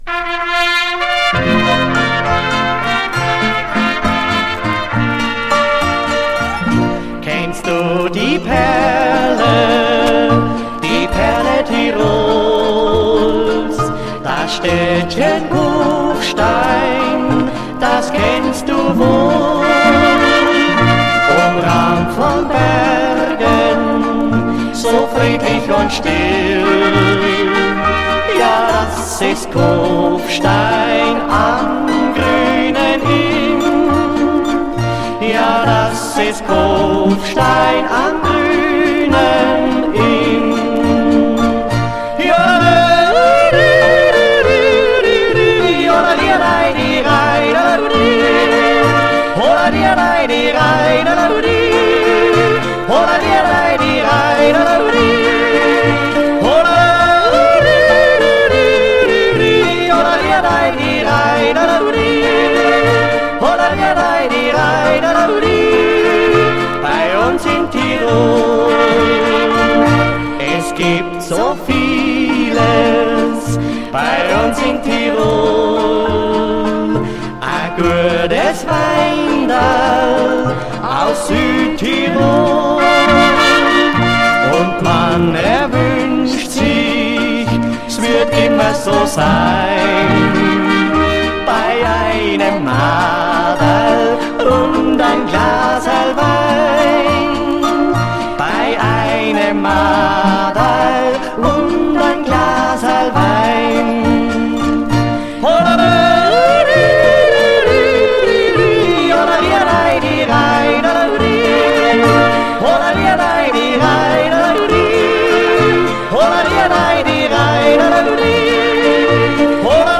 recording from LP